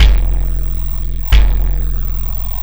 Kick Particle 02.wav